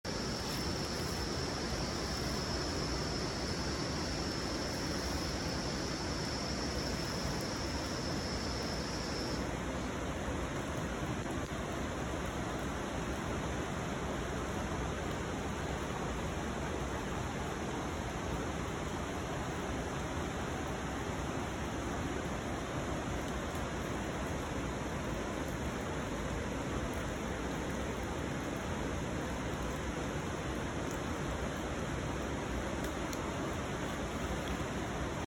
There isn’t much to see (yet), but there are a lot of jungle sounds.
We don’t know what makes the hissing noise at the start of the audio. The background hum is a small river.
LaFortunaForestSounts01.mp3